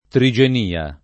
trigenia [ tri J en & a ] s. f.